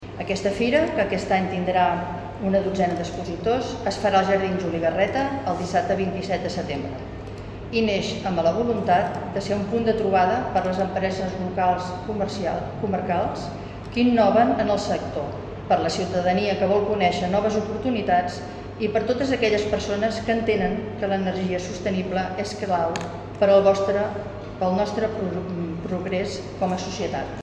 Els visitants podran conèixer propostes relacionades amb la mobilitat sostenible, les energies renovables, l’eficiència energètica, l’estalvi i la rehabilitació d’habitatges. Són declaracions de la regidora de Sostenibilitat de l’Ajuntament de Sant Feliu de Guíxols, Tili Ribera.